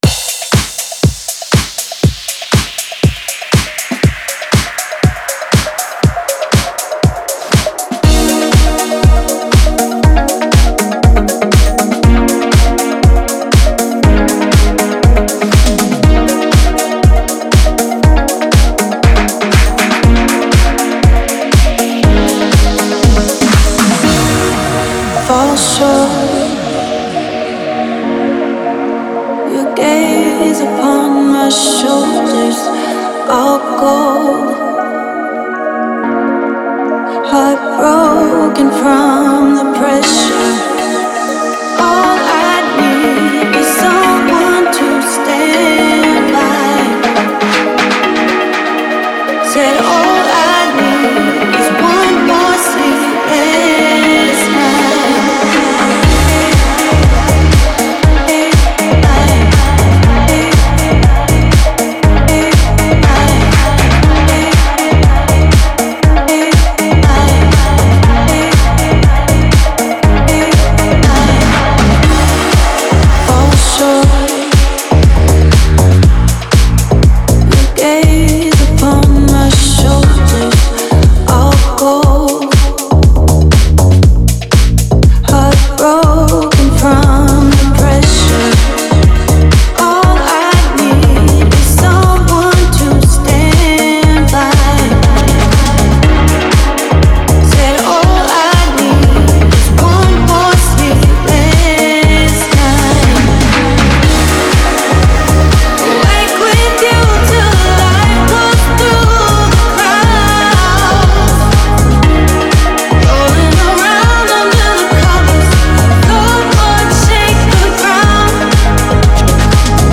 это энергичная композиция в жанре EDM